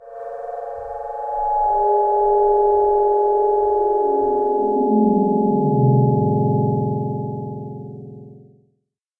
cave5.mp3